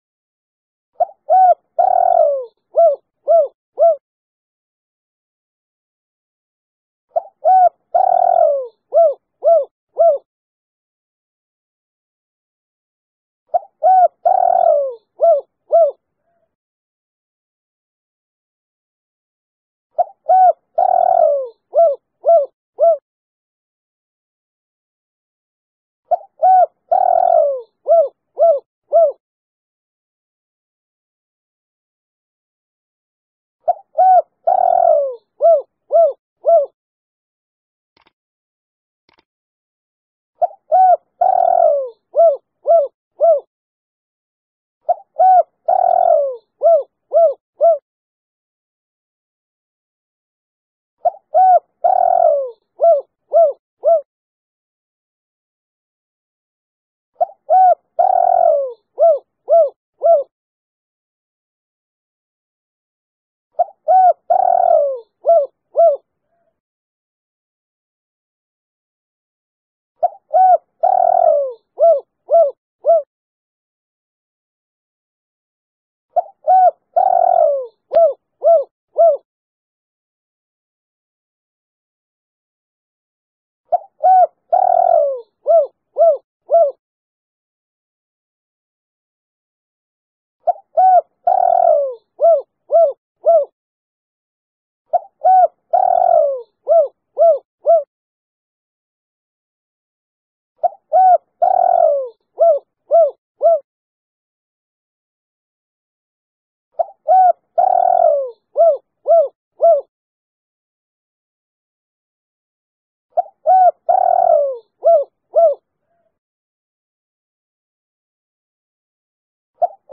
Bạn có thể download tiếng cu gáy 3 hậu mp3, tiếng chim rừng chuẩn 100% mới nhất 100%, không có tạp âm...
Tiếng cu gáy 3 Hậu
Chủ đề: tiếng chim cảnh tiếng chim rừng tiếng cu gáy
Tiếng cu gáy 3 hậu mang đến âm thanh trong rừng chân thực, rõ ràng và sống động.
tieng-cu-gay-3-hau-www_tiengdong_com.mp3